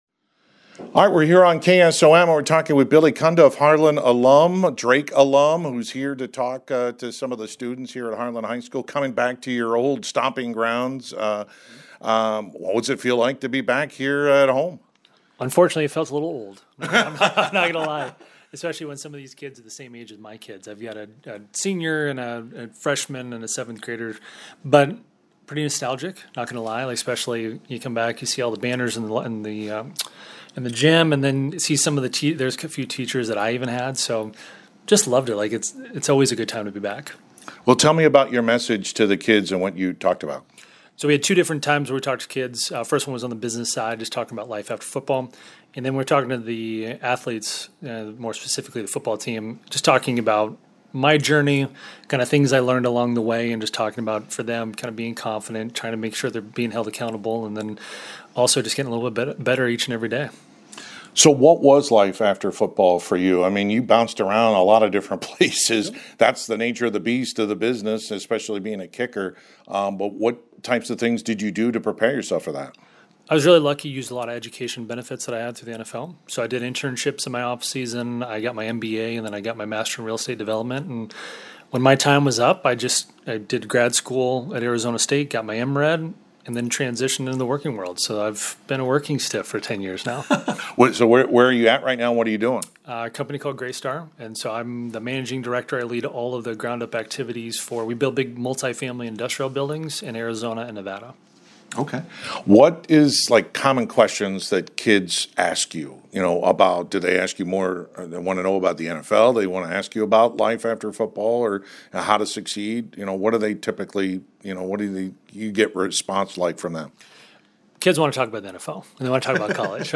Complete Interview with Billy Cundiff